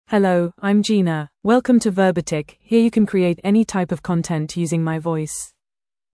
FemaleEnglish (United Kingdom)
Gina is a female AI voice for English (United Kingdom).
Voice sample
Gina delivers clear pronunciation with authentic United Kingdom English intonation, making your content sound professionally produced.